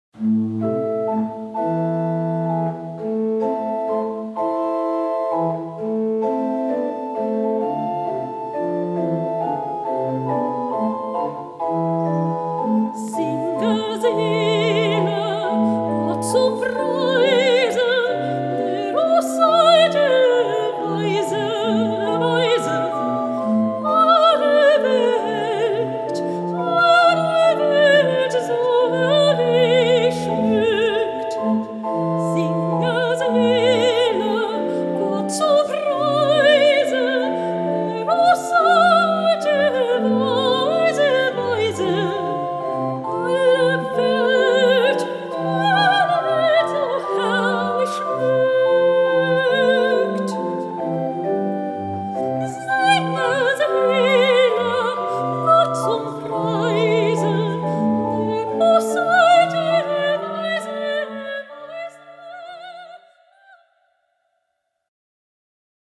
Hochzeitssängerin Hannover/Niedersachsen
Eine brilliante, warme Stimme soll dazu beitragen Ihrer Zeremonie einen feierlichen Rahmen zu geben.